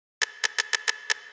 描述：Trap Rim 136BPM
Tag: 136 bpm Trap Loops Drum Loops 228.83 KB wav Key : Unknown